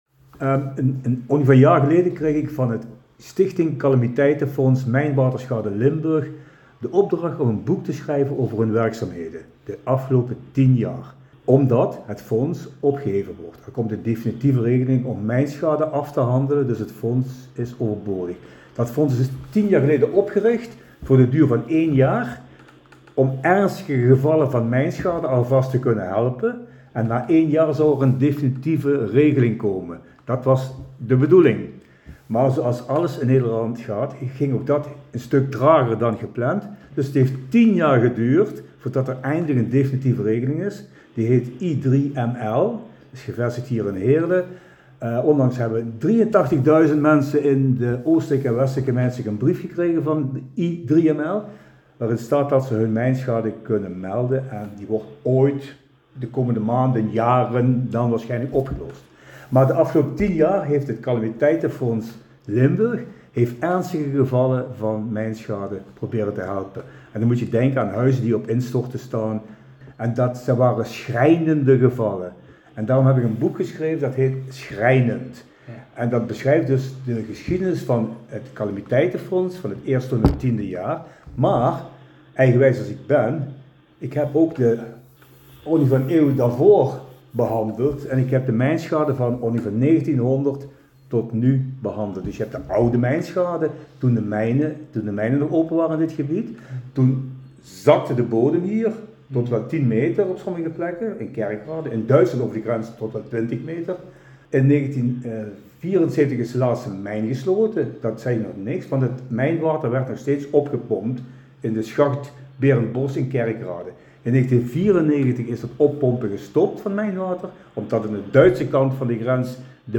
Achtergrond | In gesprek
Song name